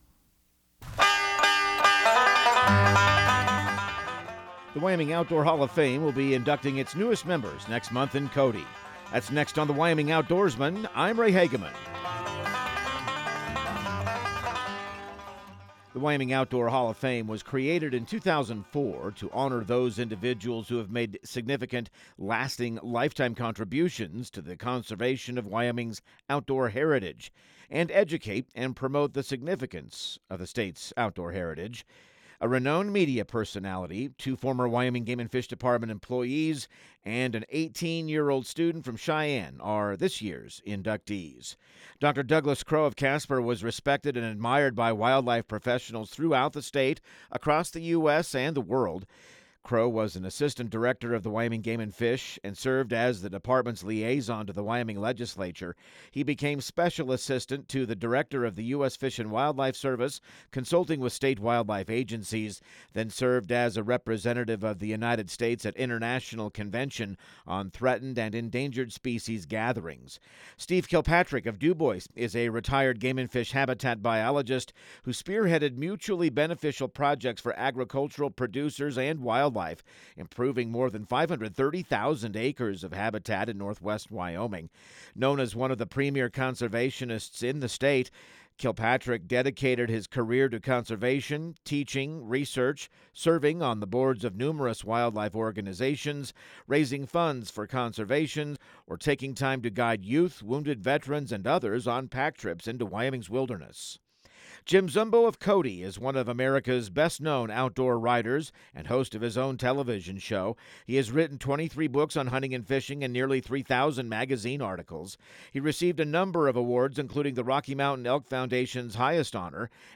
Radio news | Week of February 17